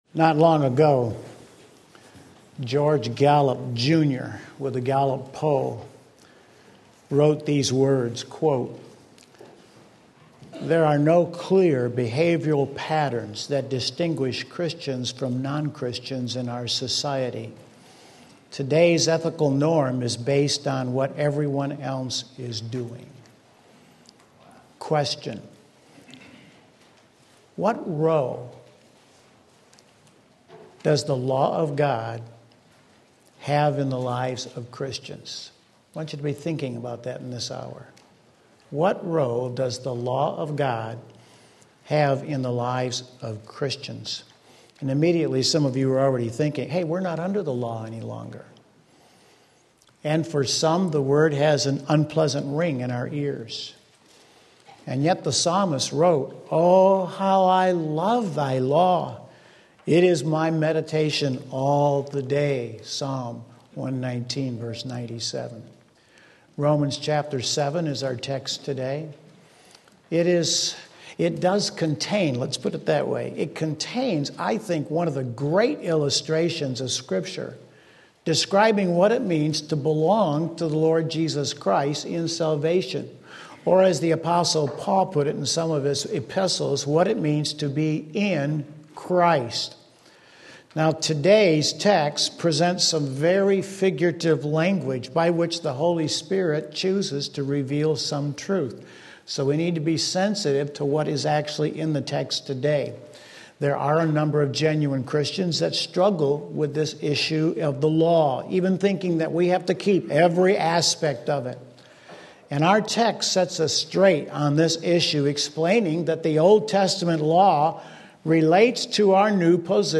Sermon Link
Wedded to Christ Romans 7:1-13 Sunday Morning Service